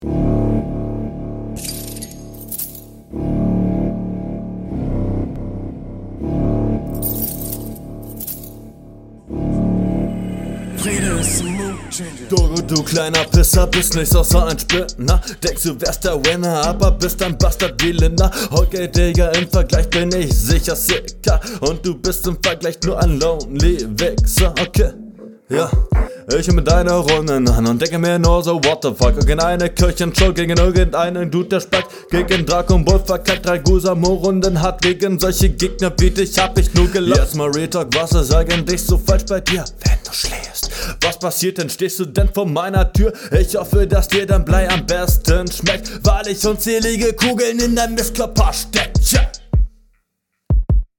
Netter freetrack, schlimmer beat, wenigstens kein gesang.
Das ist doch mal ein Beat.